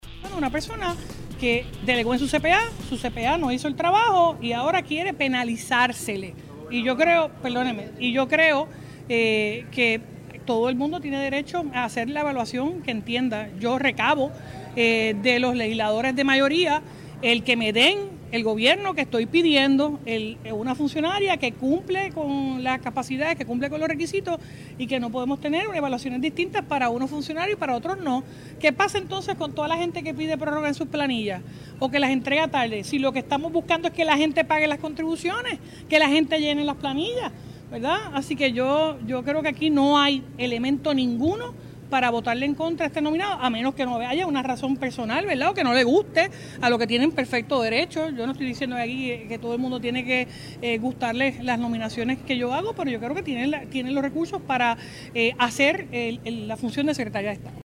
En conferencia de prensa, la primera mandataria destacó la gesta de Ferraiuoli al enfrentar la vista de unas cinco horas, y señaló cómo el pasado 30 de diciembre de 2024, cuando nominó a la secretaria, todo estaba en orden, por lo que indicó no entender por qué condenan que haya solicitado una prórroga.
428-JENNIFFER-GONZALEZ-GOBERNADORA-FIRME-EN-DEFENDER-A-FERRAIOULI-NO-VA-A-RETIRAR-SU-NOMBRAMIENTO.mp3